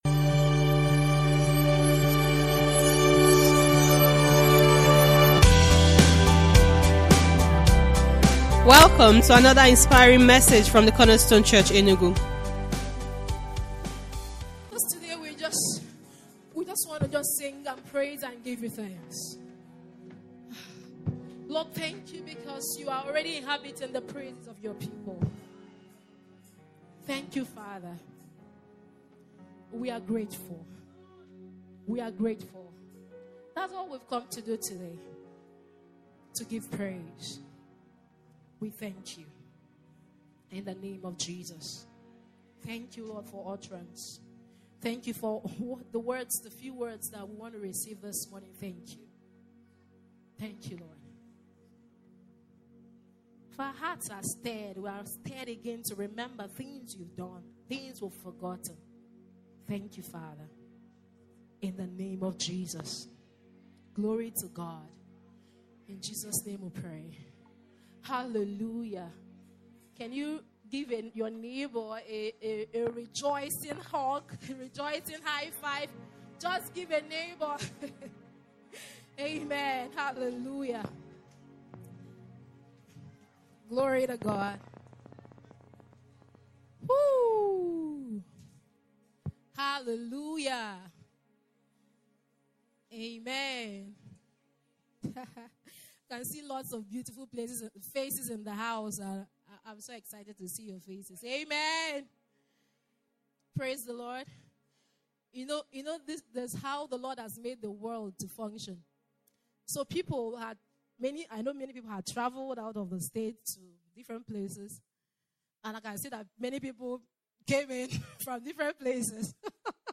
Topic: TCC Annual Thanksgiving Service